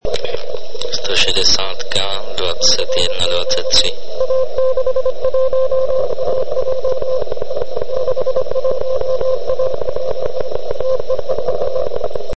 Měl dokonalý signál na všech bandech ( zde záznam ze 160m )